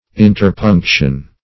Search Result for " interpunction" : The Collaborative International Dictionary of English v.0.48: Interpunction \In`ter*punc"tion\, n. [L. interpunctio, fr. interpungere, interppunctum, to interpoint.